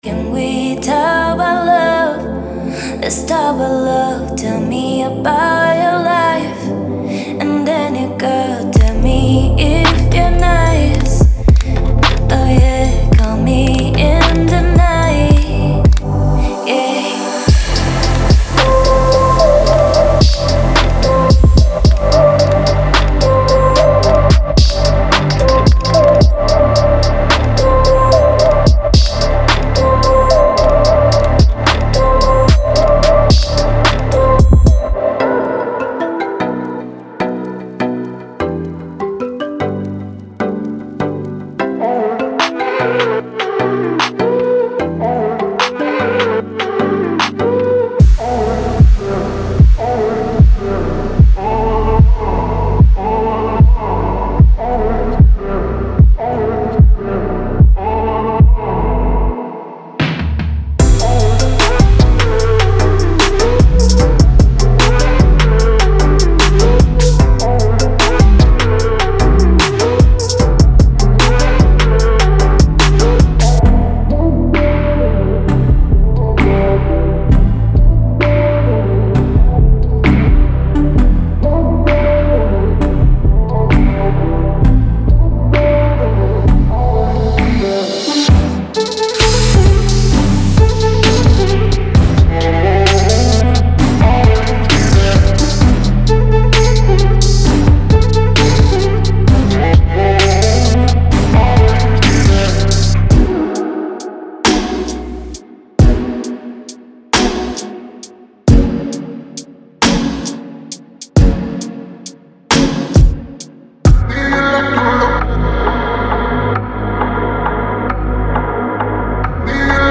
Genre:Chill Trap
最新のサウンドを詰め込んだ、ソウルフルなトラップとヒップホップの新コレクションです。
デモサウンドはコチラ↓
7 Synth Loops (Pads, Plucks, Leads)
17 Vocal Loops (Pitched, Dry, Wet, Distorted)
1 Piano Loop